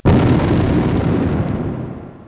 Totally Free War Sound Effects MP3 Downloads
Explosion.mp3